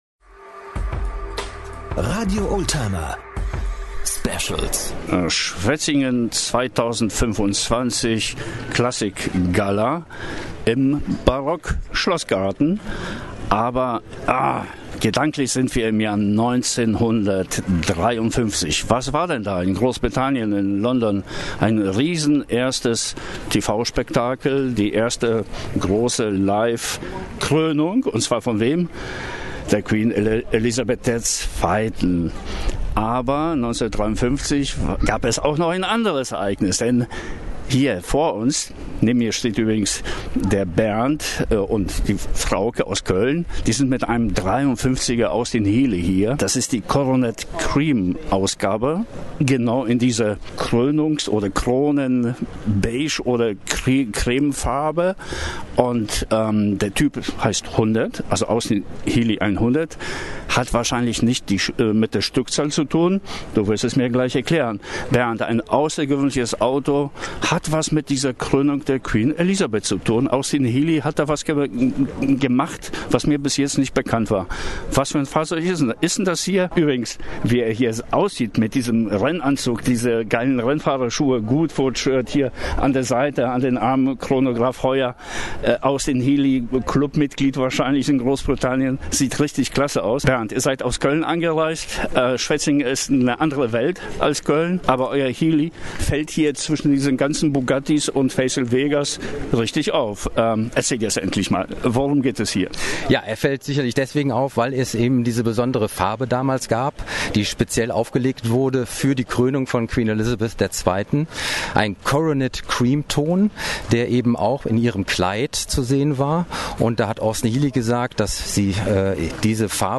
Einen entdeckten wir auf der Classic Gala Schwetzingen.